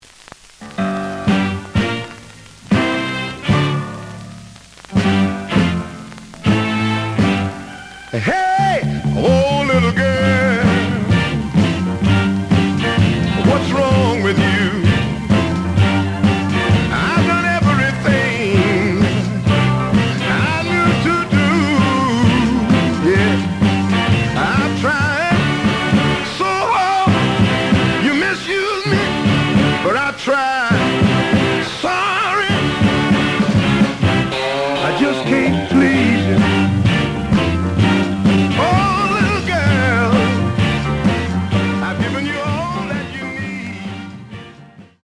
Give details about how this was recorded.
The audio sample is recorded from the actual item. Slight affect sound.)